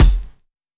Dilla Kick 08.wav